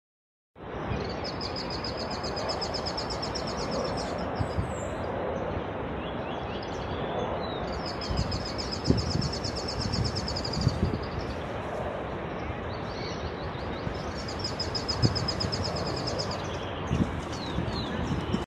Curutié Ocráceo (Limnoctites sulphuriferus)
Nombre en inglés: Sulphur-bearded Reedhaunter
Localidad o área protegida: Reserva Ecológica Costanera Sur (RECS)
Condición: Silvestre
Certeza: Observada, Vocalización Grabada